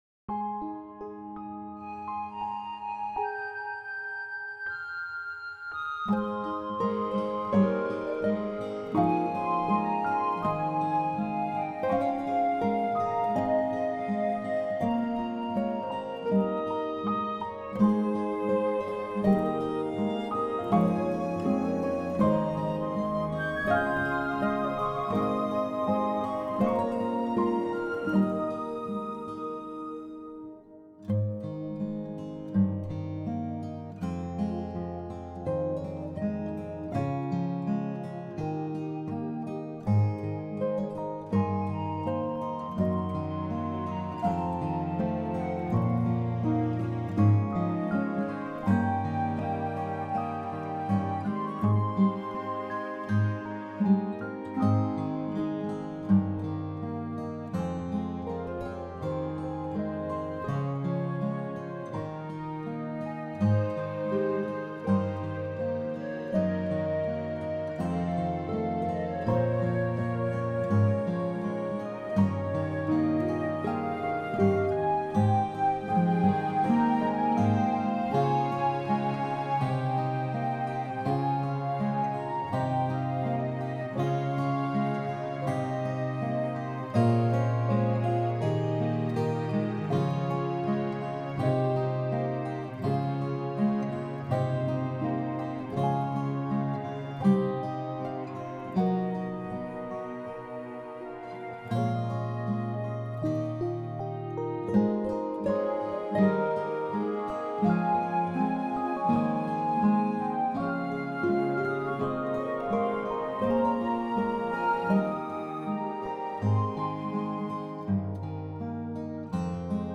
The three songs for this medley are all love songs that I composed before the age of 20. “You Are My Wings” and “Song of Joy” are actually wedding songs.